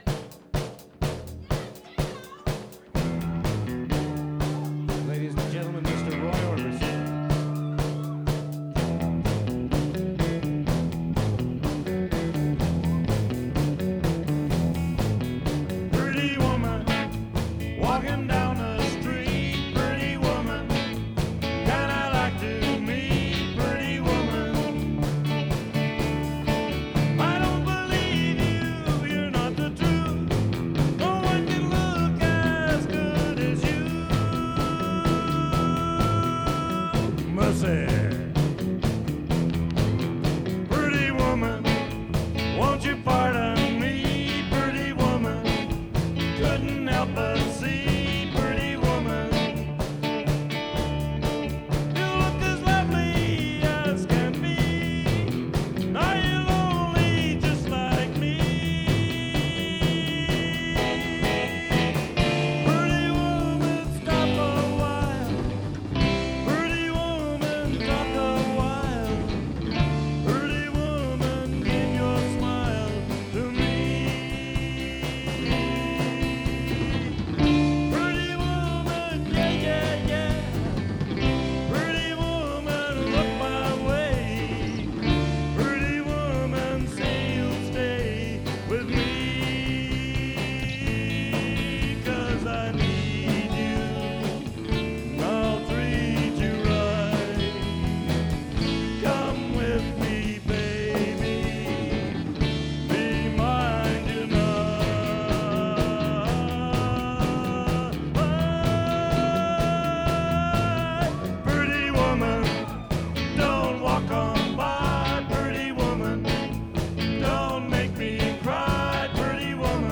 drums
guitar
live at the Special Olympics 25th anniversary
keeping us on tempo
trying to stretch for that high harmony.